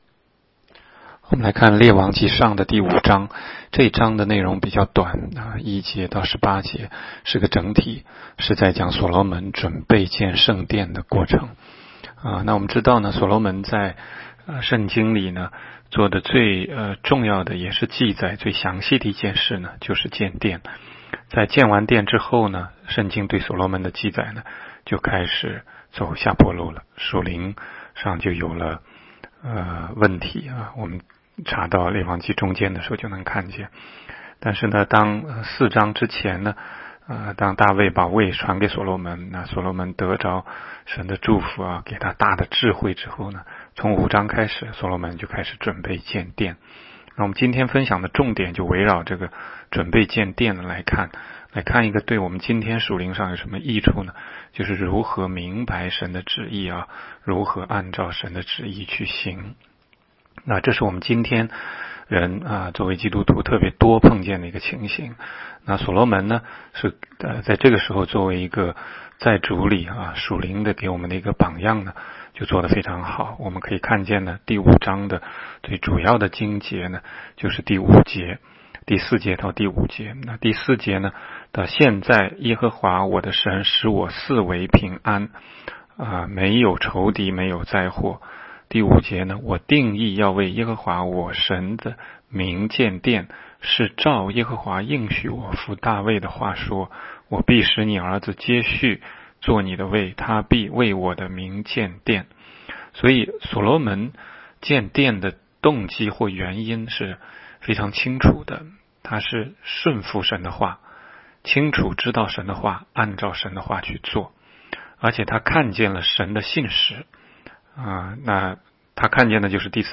16街讲道录音 - 每日读经-《列王纪上》5章